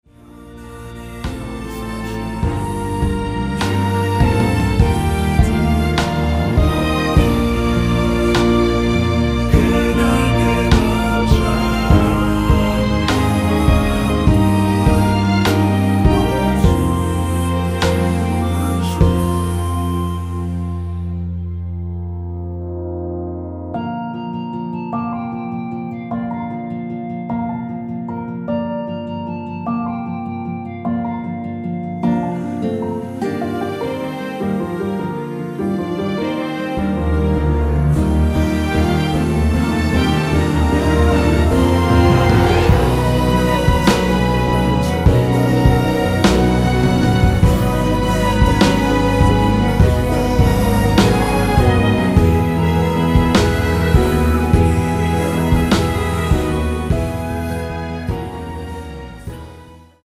원키에서(-2)내린 멜로디와 코러스 포함된 MR입니다.(미리듣기 확인)
Bb
앞부분30초, 뒷부분30초씩 편집해서 올려 드리고 있습니다.
중간에 음이 끈어지고 다시 나오는 이유는